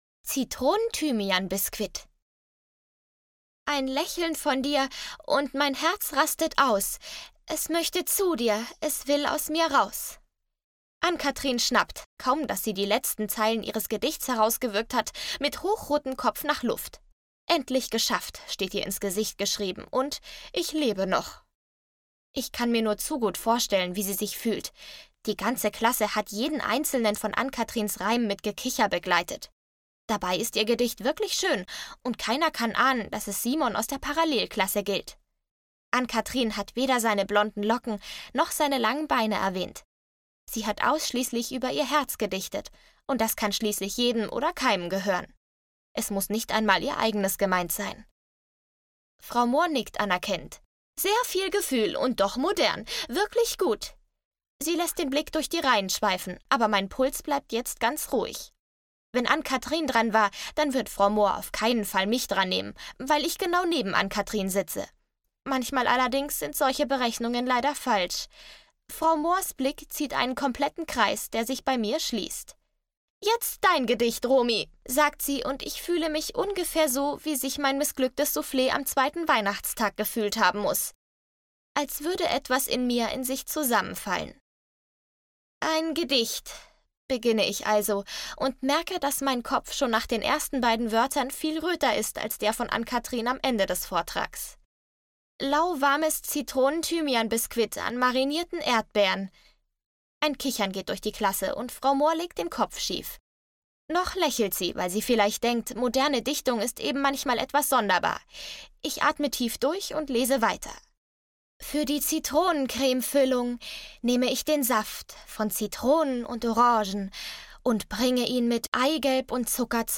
Freche Mädchen: Drei Küsse zum Dinner - Sabine Both - Hörbuch